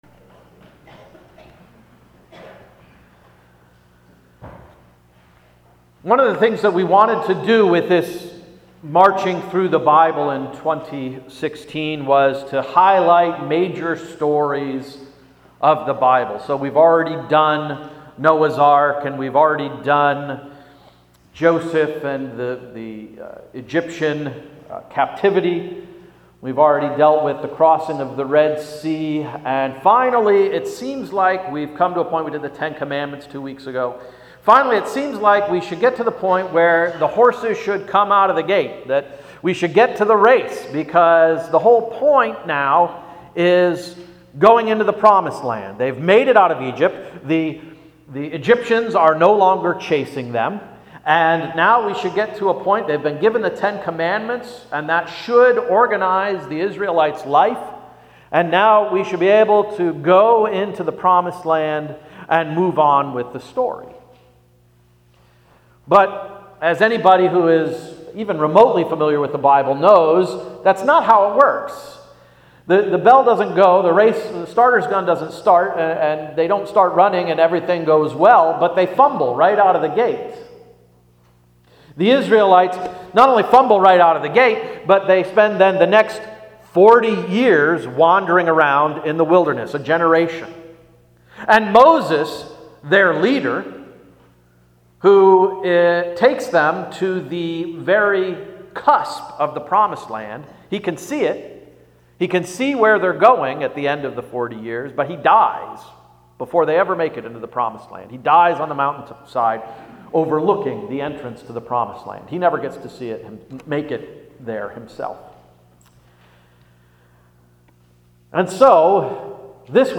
May 22, 2016 Sermon–“And They’re Off . . . “